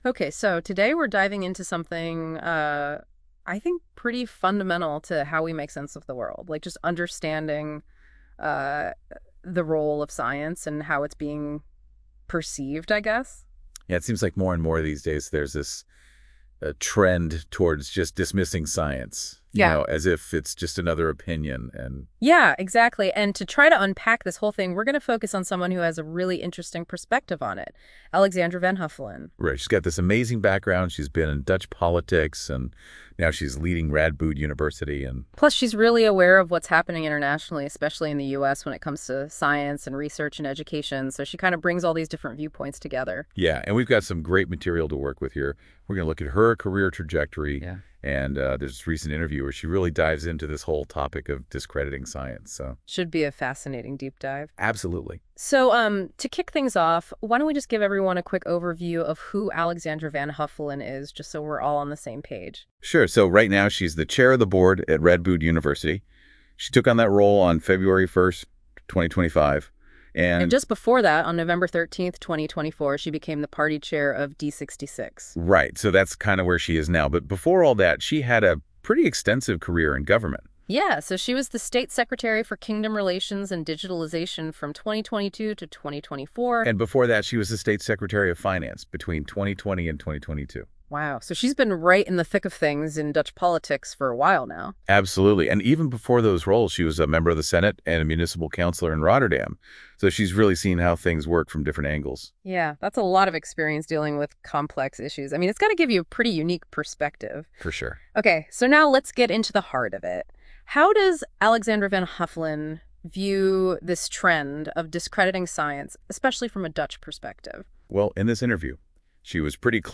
BTW - This is a podcast in an interview style (much more dynamic) and much more engaging! read more